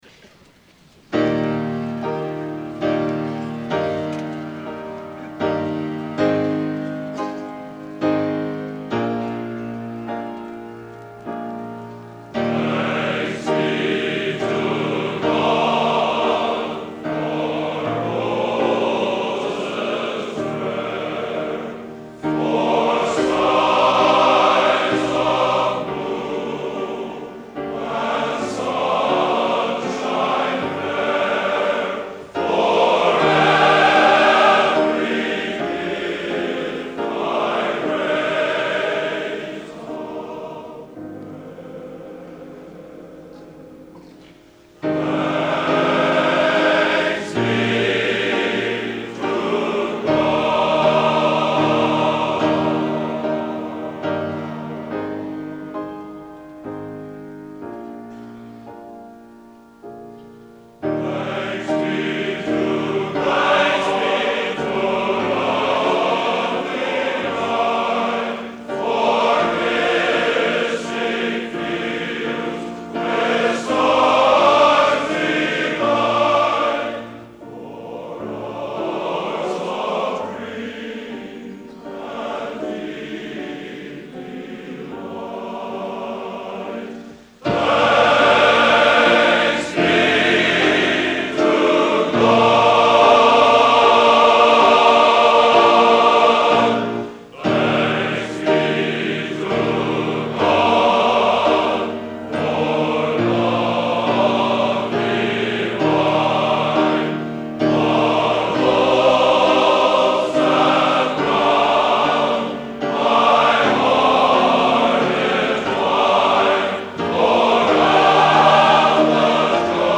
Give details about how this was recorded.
Location: West Lafayette, Indiana